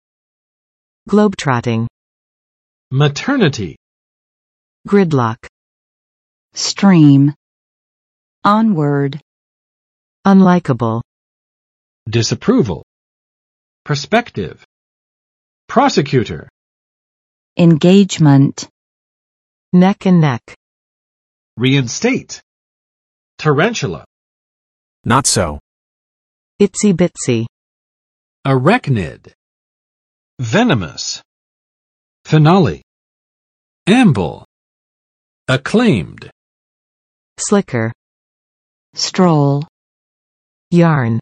[ˋglob͵trɑtɪŋ] n. 环球旅行